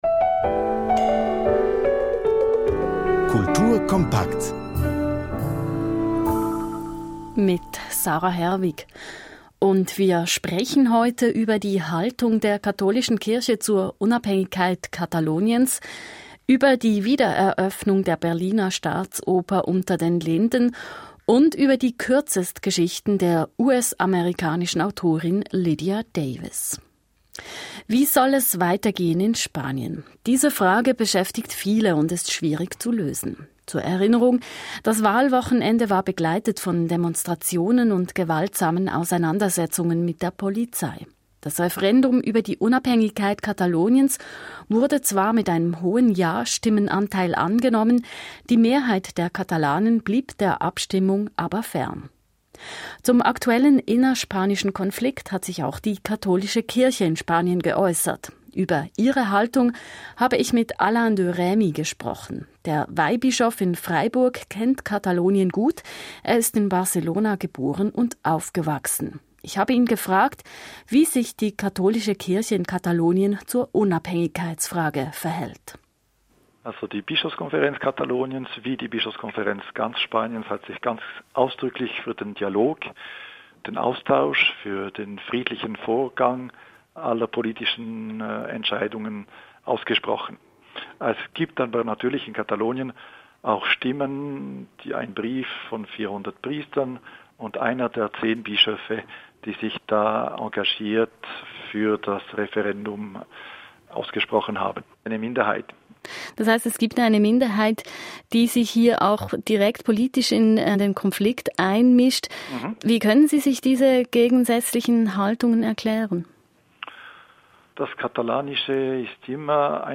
Kultur-kompakt, Podcast der Sendung auf SRF 2 vom 4. Oktober 2017
Konzertbesprechung des Konzertes vom 29.9.2017 im Centre Le Phoenix in Fribourg